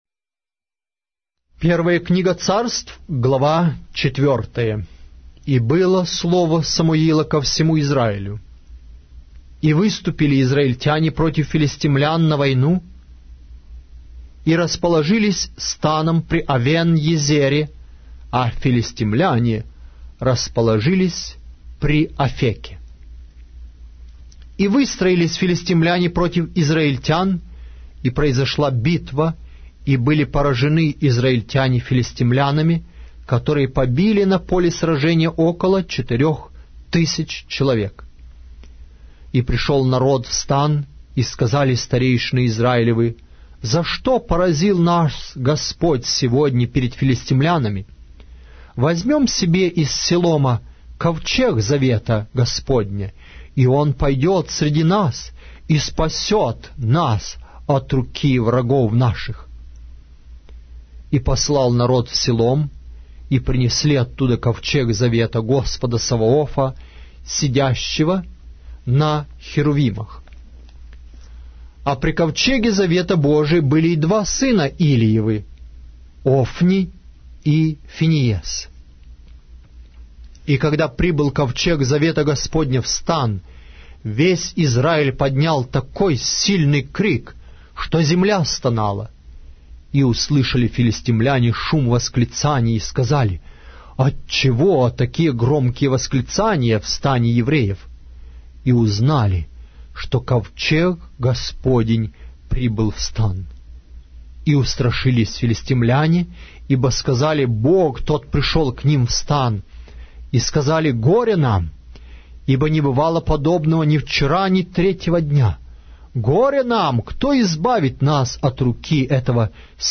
Глава русской Библии с аудио повествования - 1 Samuel, chapter 4 of the Holy Bible in Russian language